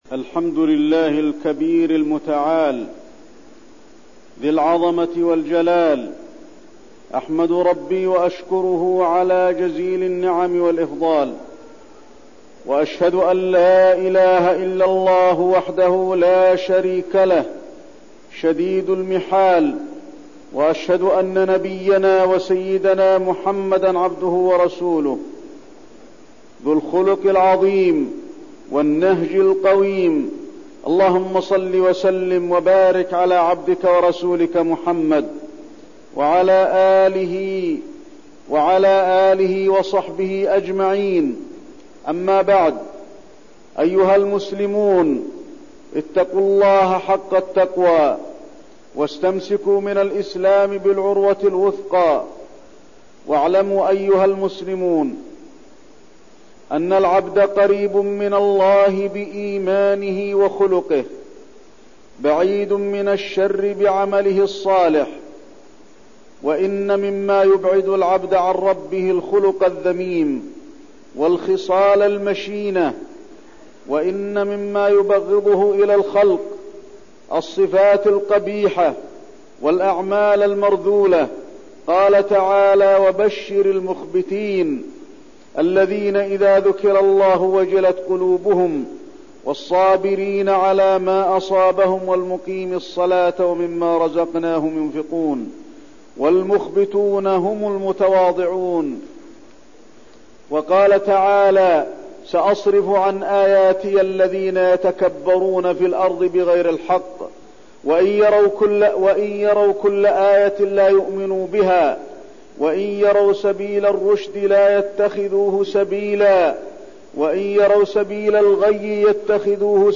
تاريخ النشر ٥ ربيع الأول ١٤١٢ هـ المكان: المسجد النبوي الشيخ: فضيلة الشيخ د. علي بن عبدالرحمن الحذيفي فضيلة الشيخ د. علي بن عبدالرحمن الحذيفي الكبر